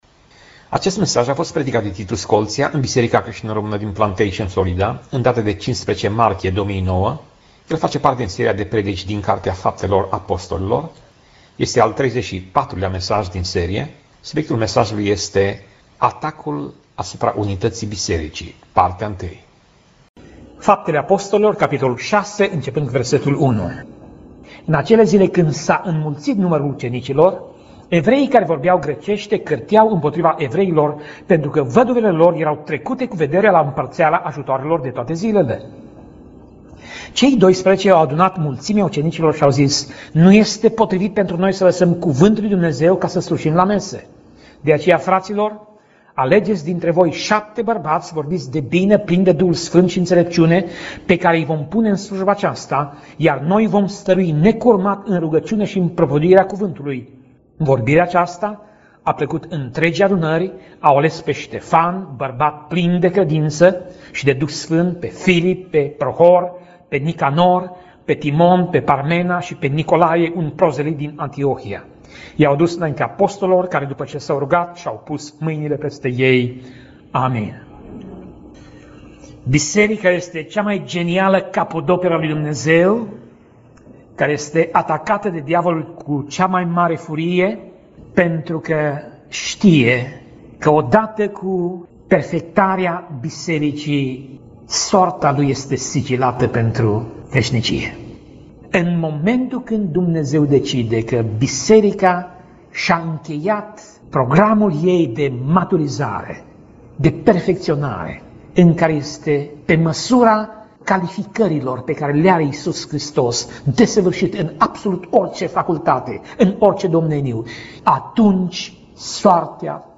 Pasaj Biblie: Faptele Apostolilor 6:1 - Faptele Apostolilor 6:7 Tip Mesaj: Predica